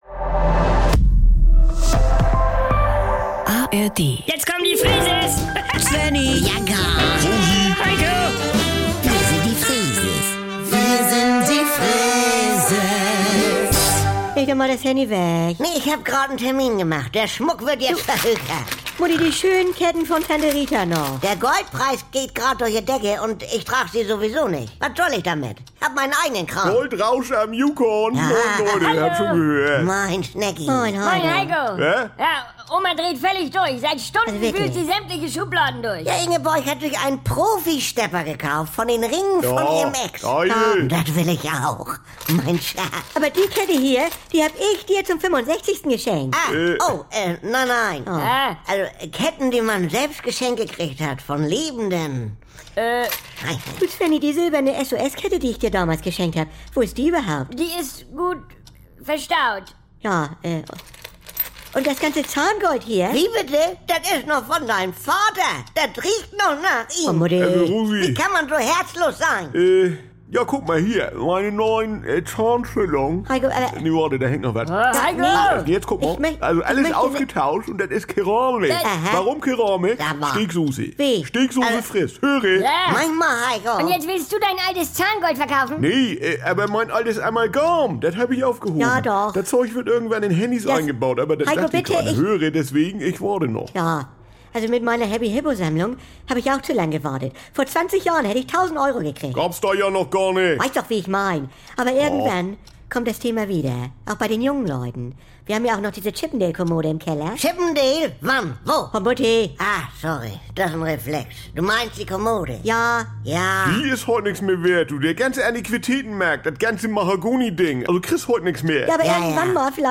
Saubere Komödien NDR 2 Komödie Unterhaltung NDR Freeses Comedy